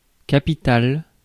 Ääntäminen
IPA: /ka.pi.tal/